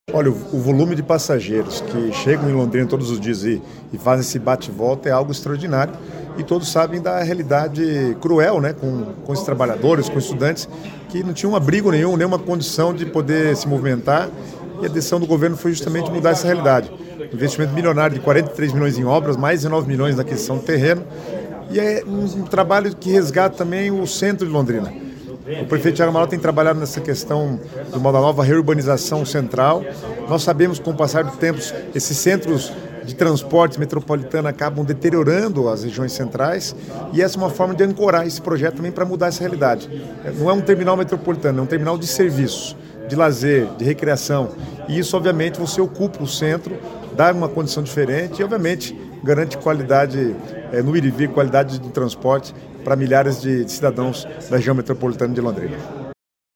Sonora do secretário das Cidades, Guto Silva, sobre o edital para construção do Terminal Metropolitano de Londrina